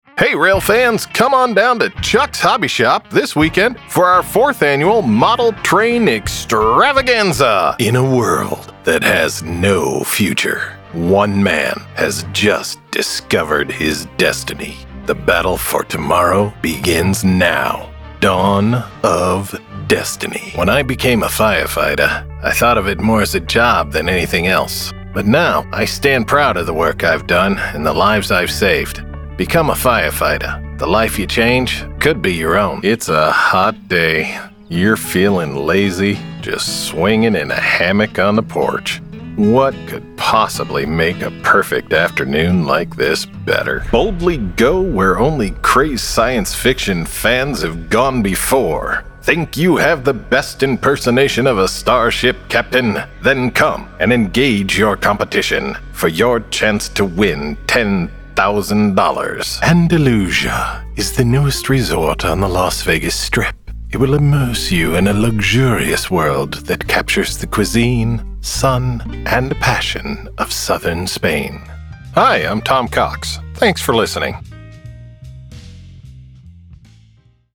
Character Demo
Middle Aged